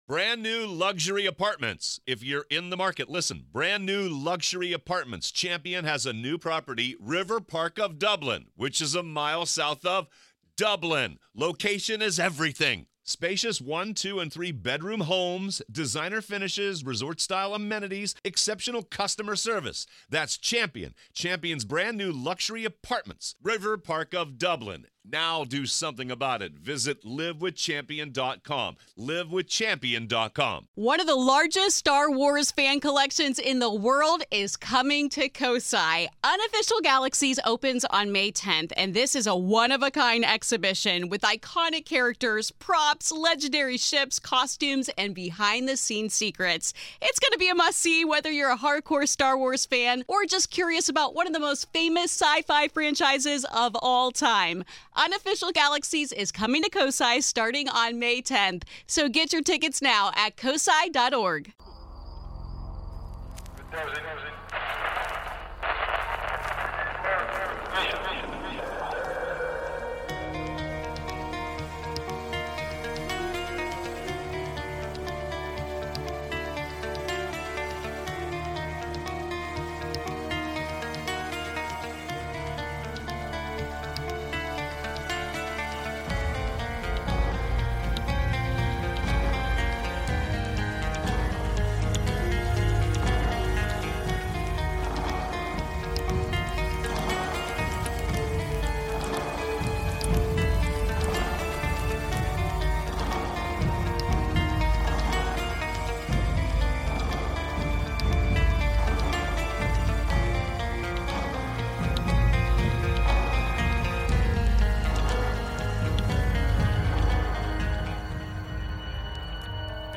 Tonight I discuss a first for the show, calls include UFOs over Montana, Ghosts in Kentucky and a shadow being with a menacing nickname.